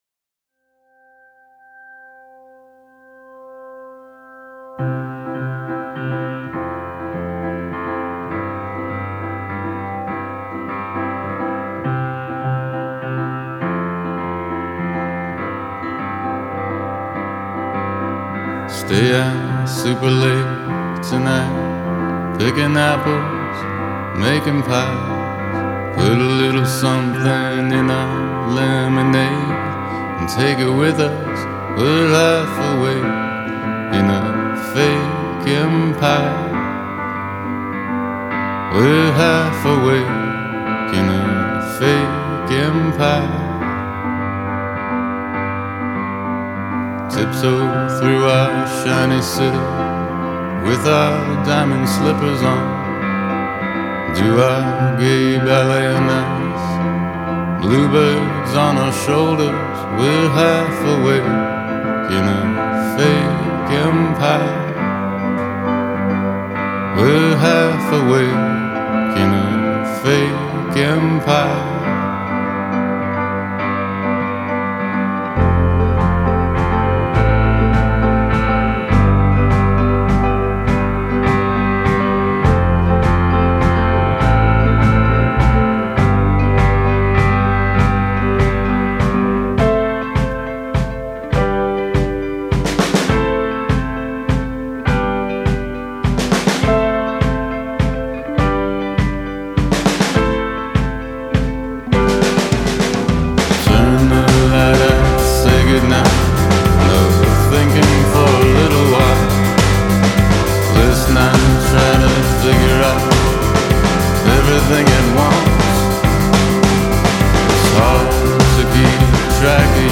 matching the gorgeously moody music within